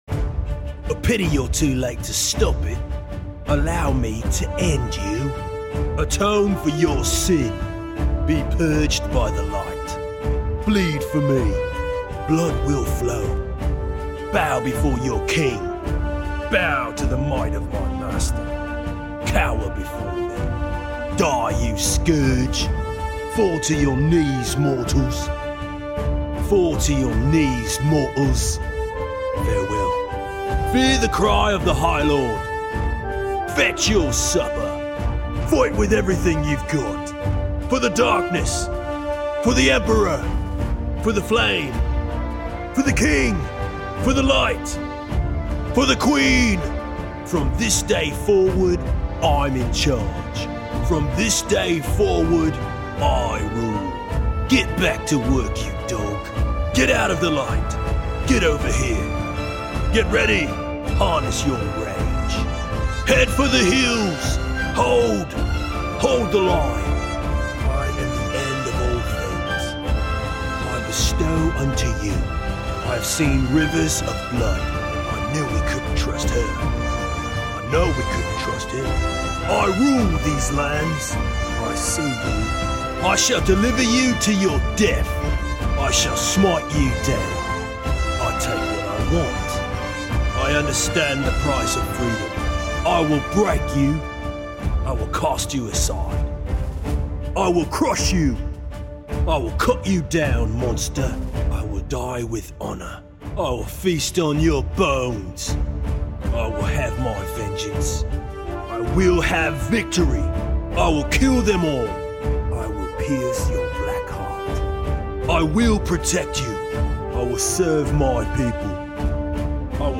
这是一个干净的英语口音和风格的配音文件集合，专为奇幻游戏、MMORPG 游戏和电影预告片设计！这个配音音效库以一位随时准备战斗和游戏的男性主角为主角。
英国武士的声音质感干脆利落、干净利落，富有质感。
他的声音开箱即用，但足够清晰，可以进行进一步处理。
• 9 种呼吸文件——死亡呼吸、气喘、疲倦、虚弱等等
• 9 种咳嗽录音 – 剧烈咳嗽、窒息咳嗽、急促咳嗽、吸烟咳嗽、喉咙咳嗽
• 9 种战斗声音——咆哮、冲锋、挥动、刺拳、受伤、猛推
• 8 种笑声——邪恶、疯狂、滑稽、普通、轻柔、悠长、爆炸